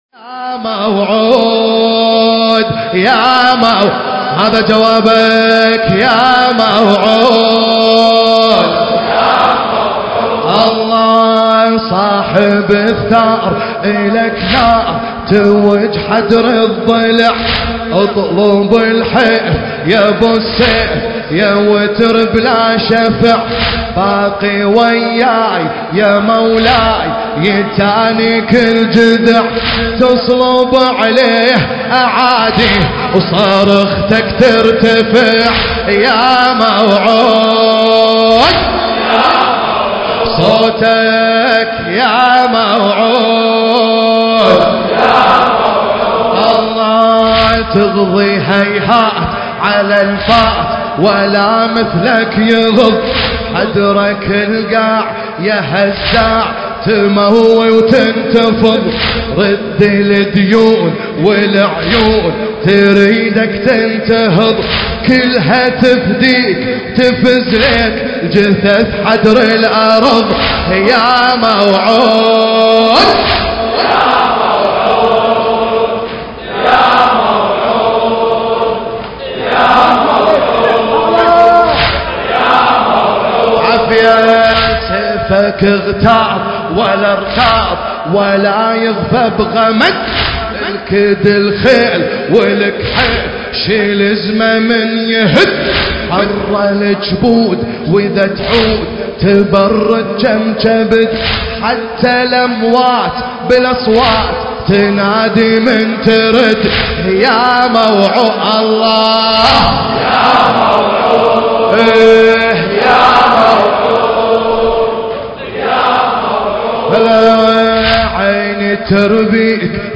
المكان: حسينية المرحوم داود العاشور